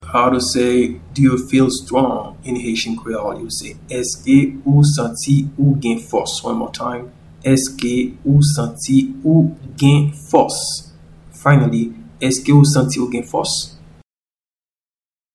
Pronunciation and Transcript:
Do-you-feel-strong-in-Haitian-Creole-Eske-ou-santi-ou-gen-fos.mp3